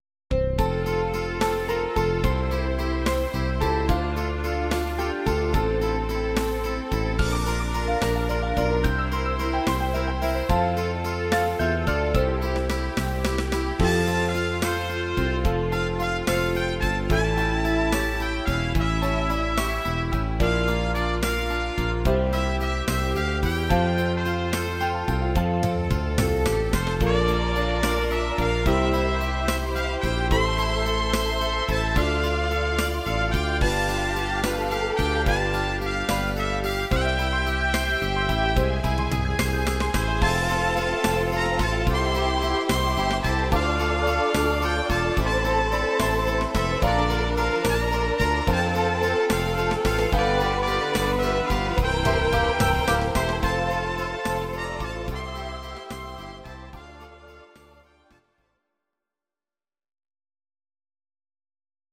Please note: no vocals and no karaoke included.